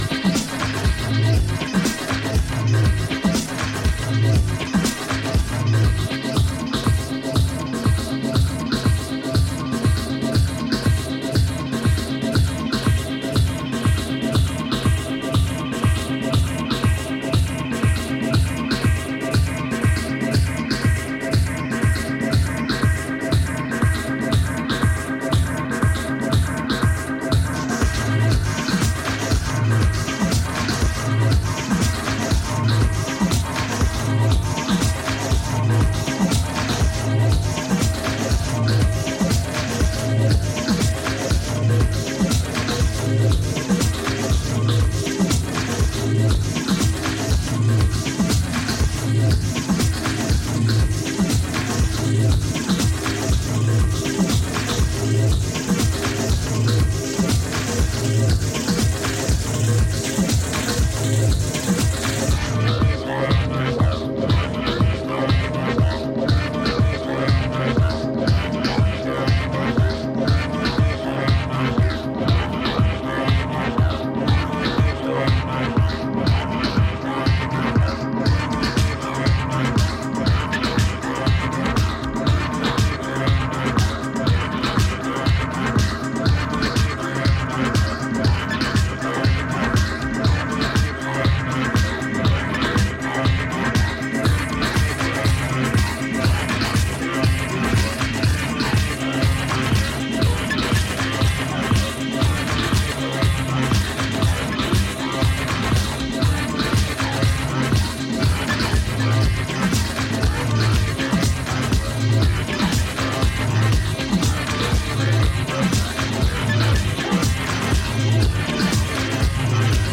Morphing rhythms cycle through shifting landscapes